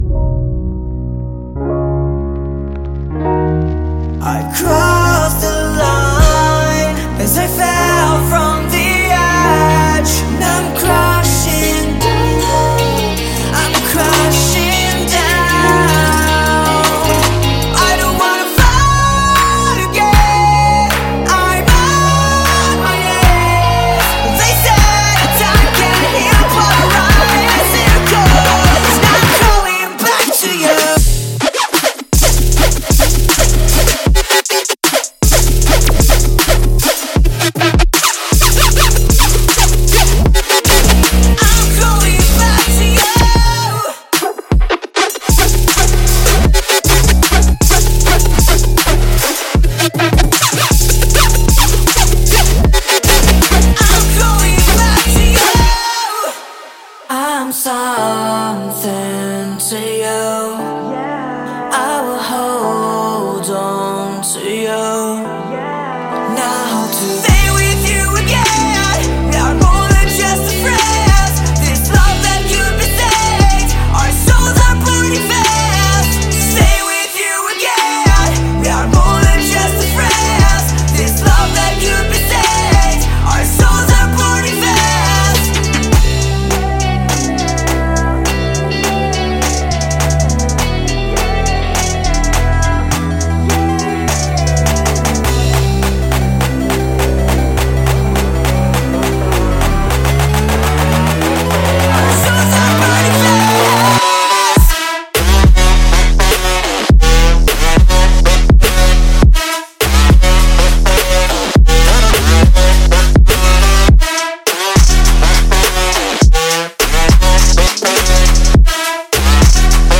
3. Trap